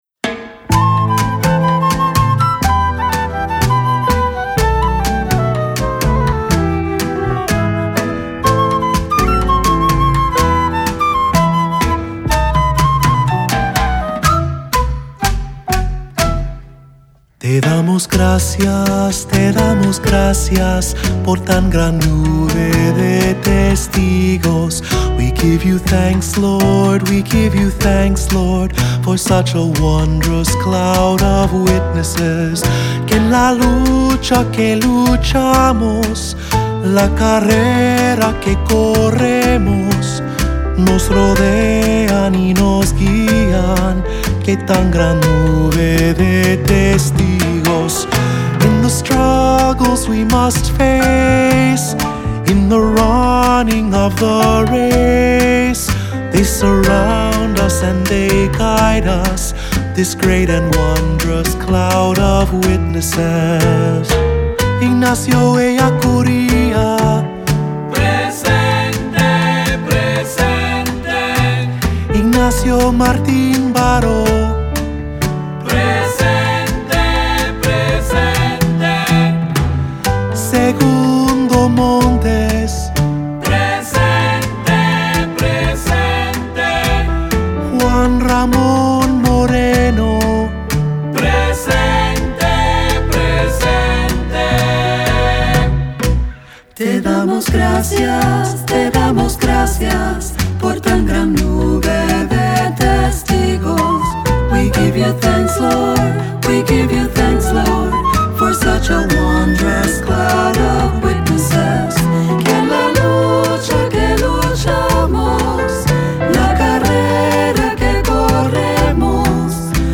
Voicing: Three-part; Cantor; Assembly